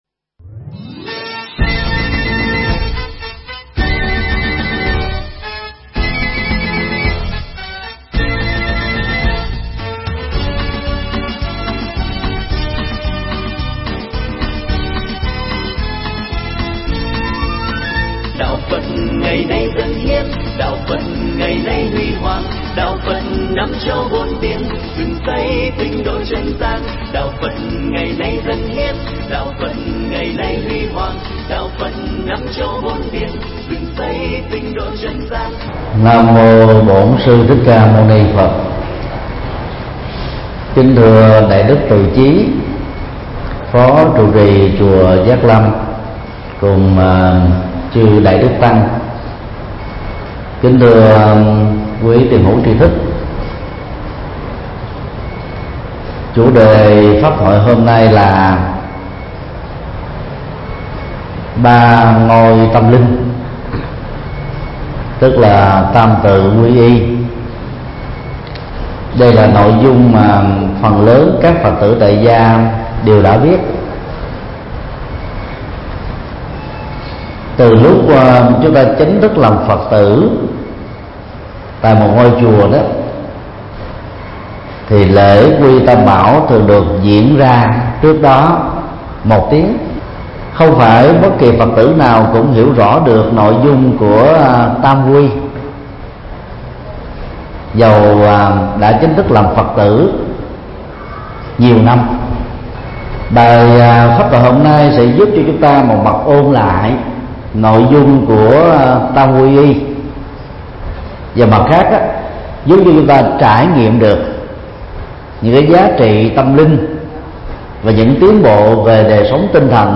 Ba ngôi tâm linh – TT Thích Nhật Từ Thuyết Giảng mp3
Mp3 Thuyết Pháp Ba ngôi tâm linh – TT. Thích Nhật Từ Giảng tại chùa Giác Lâm 565 Lạc Long Quân, Phường 10, Tân Bình, Hồ Chí Minh, ngày 3 tháng 5 năm 2015